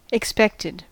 Ääntäminen
IPA : /ɪksˈpɛktɪd/